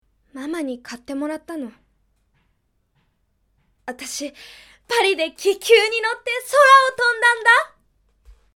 無邪気な少女
ボイスサンプル